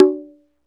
Conga_4.wav